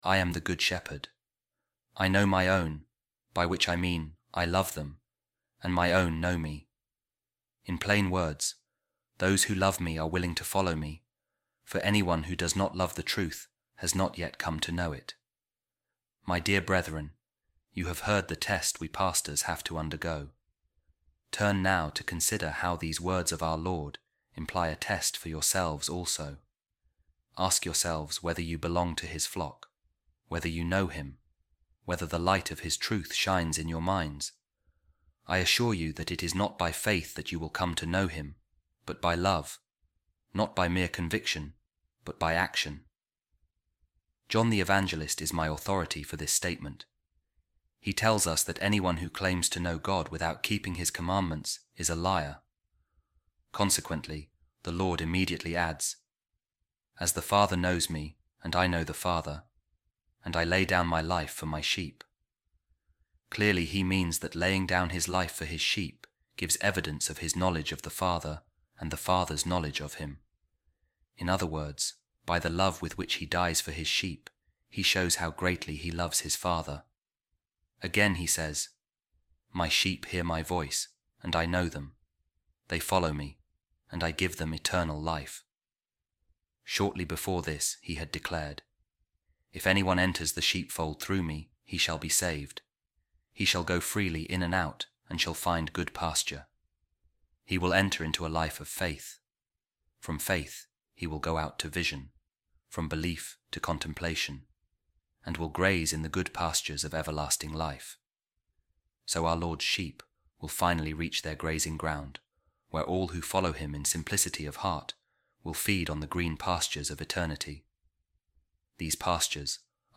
Office Of Readings | Eastertide Week 4, Sunday | A Reading From The Homilies Of Pope Saint Gregory The Great On The Gospels | Jesus Christ The Good Shepherd